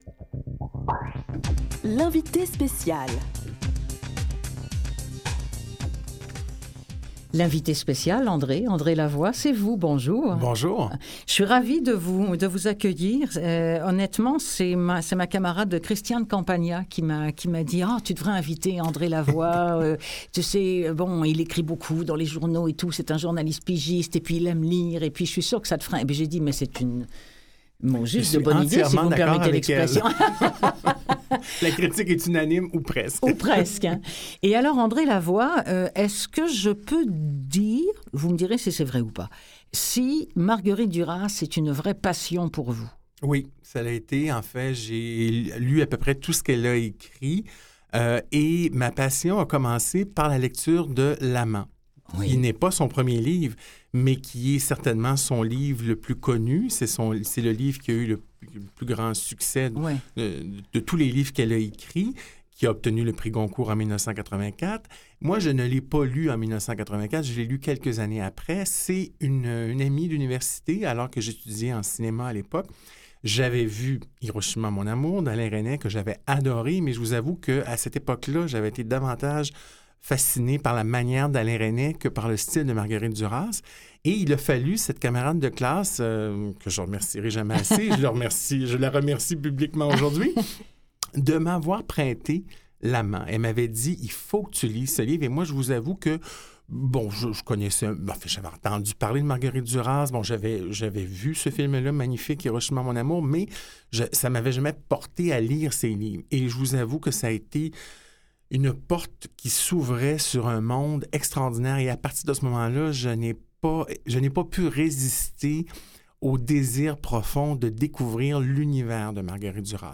Il sera spécialement question du livre L’Amant. -> Écouter l’entrevue ici.